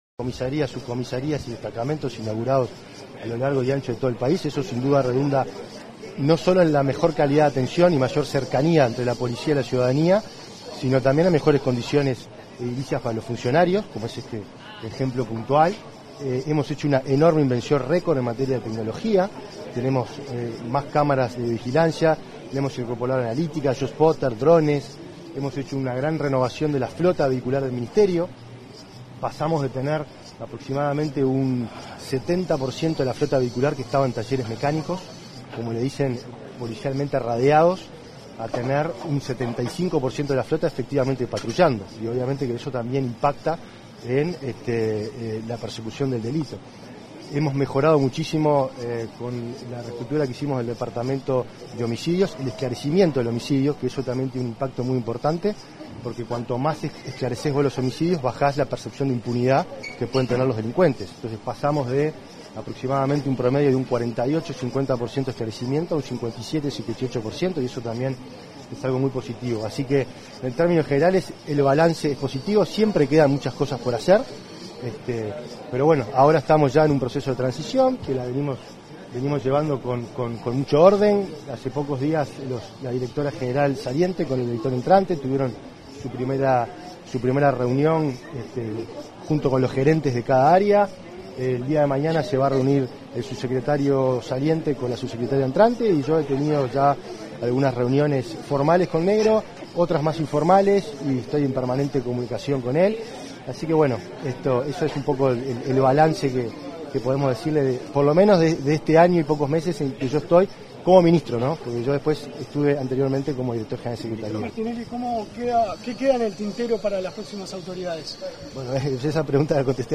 Declaraciones del ministro del Interior, Nicolás Martinelli
Declaraciones del ministro del Interior, Nicolás Martinelli 13/02/2025 Compartir Facebook X Copiar enlace WhatsApp LinkedIn Tras la reinauguración de la oficina de la Dirección Nacional de Identificación Civil en San Carlos, este 13 de febrero, el ministro del Interior, Nicolás Martinelli, efectuó declaraciones a la prensa.